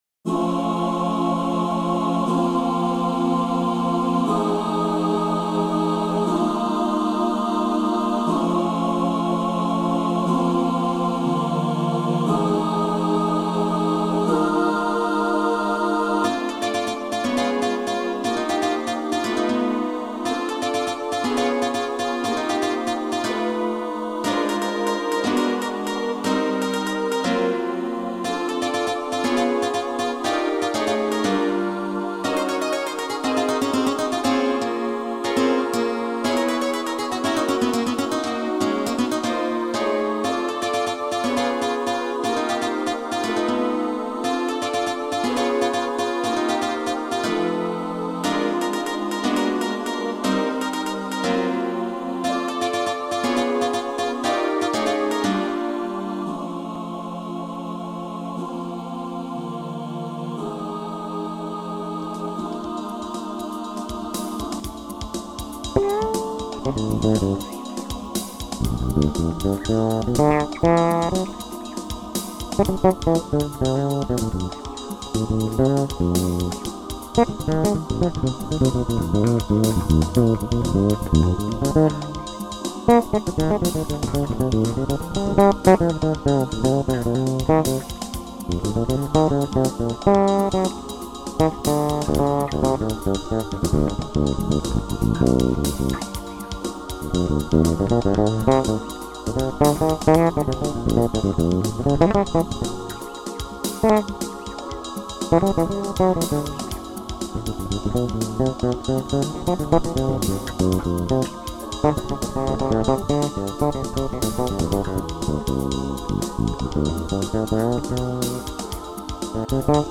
Style JAZZ